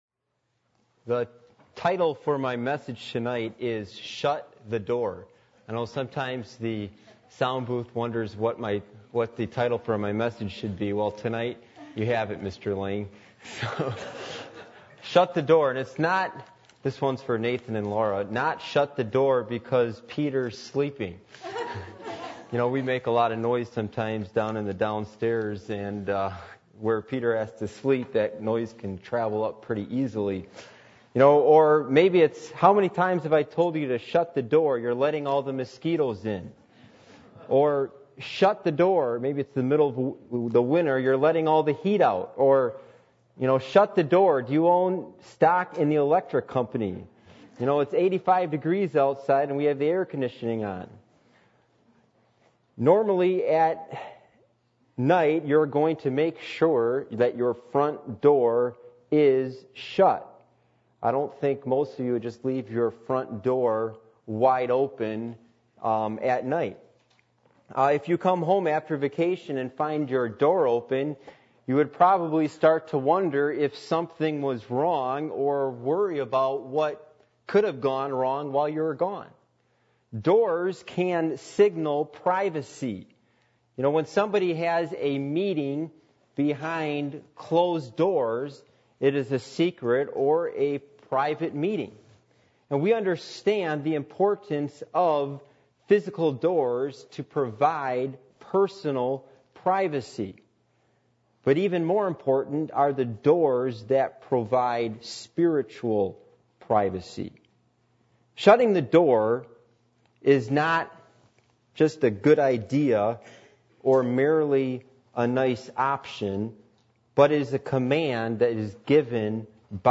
Matthew 6:1-6 Service Type: Midweek Meeting %todo_render% « Childlike Faith Christian Home Series